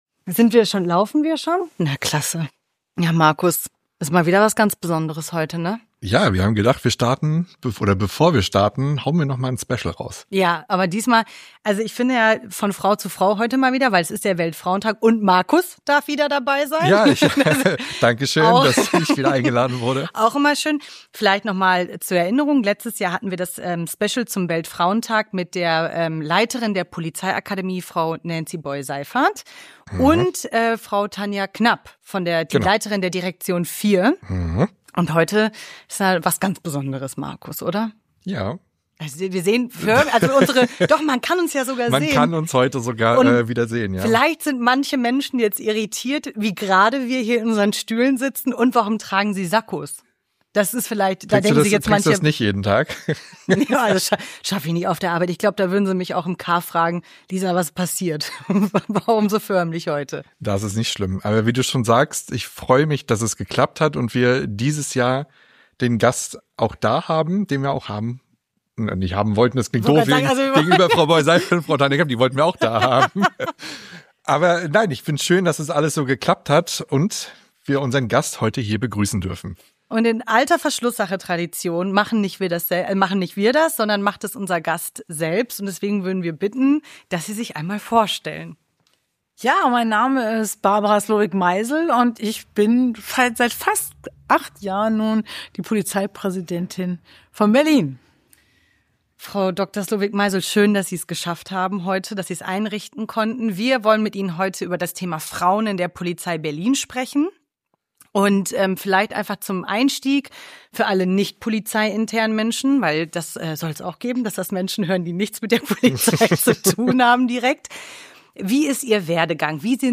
Vielleicht liegt es am Special im Special, denn keine andere als unsere Polizeipräsidentin Frau Dr. Barbara Slowik Meisel ist in dieser Folge zu Gast.
Es wird reflektiert, nach vorne geschaut und vor allen Dingen wird miteinander gelacht.